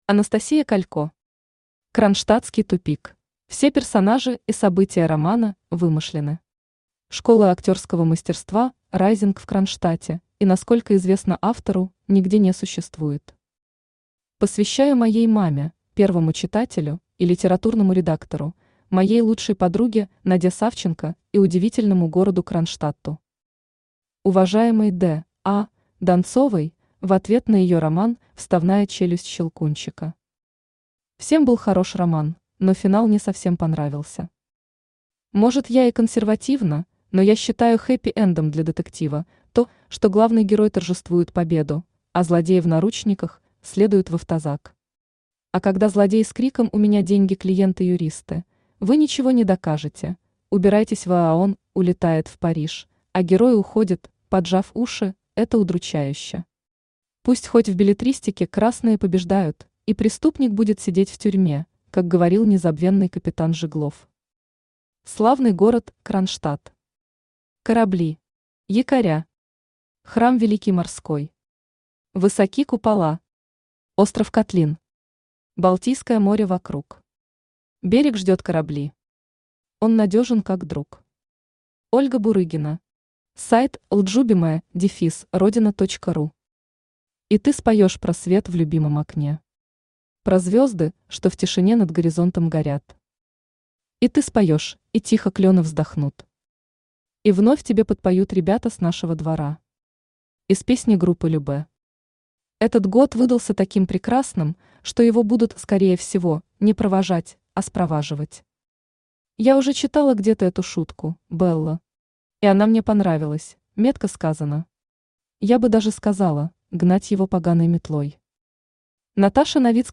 Аудиокнига Кронштадтский тупик | Библиотека аудиокниг
Aудиокнига Кронштадтский тупик Автор Анастасия Александровна Калько Читает аудиокнигу Авточтец ЛитРес.